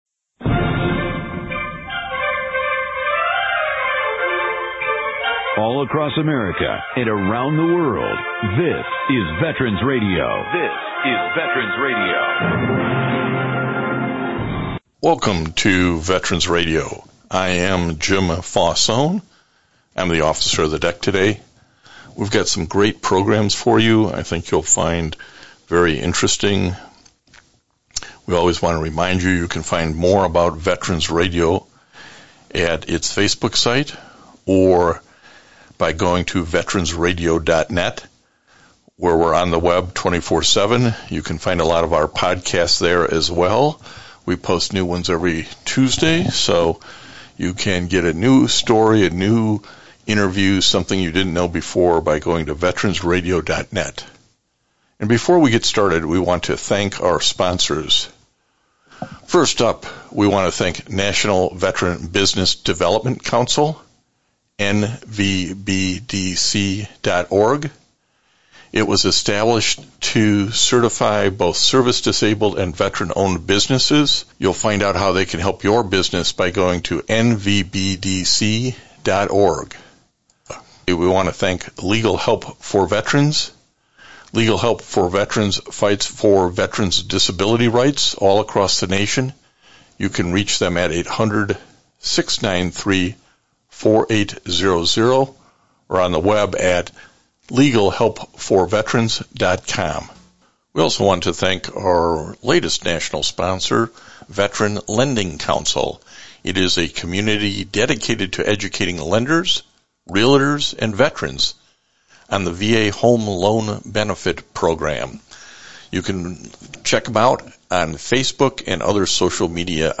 This week’s one hour radio broadcast is pre-recorded.
Interviews